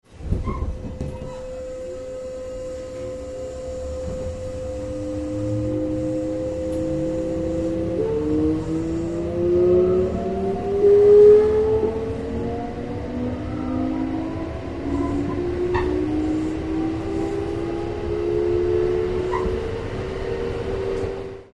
2.　変調音
こちら（ギア比5.73）：VX05 / VX06